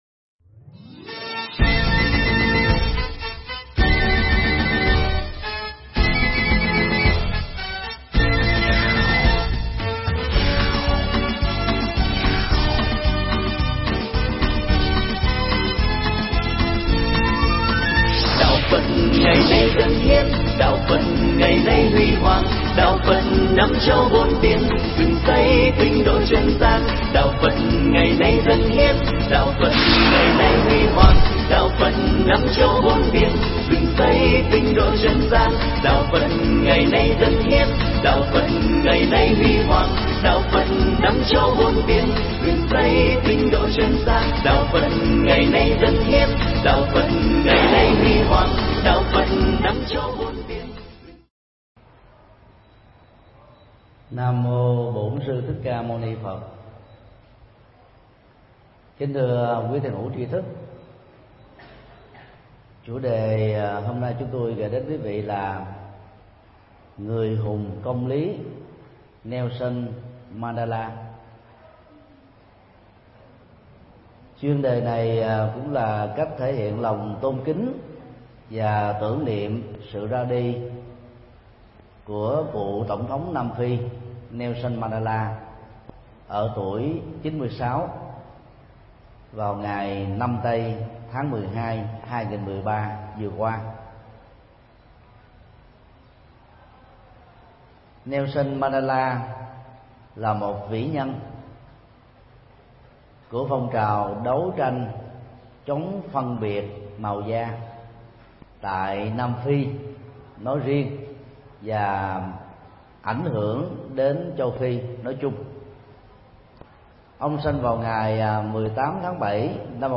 Pháp thoại
Giảng tại chùa Xá Lợi, Quận 3